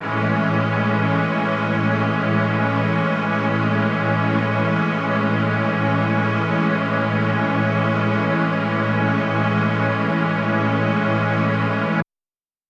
SO_KTron-Ensemble-Amaj7.wav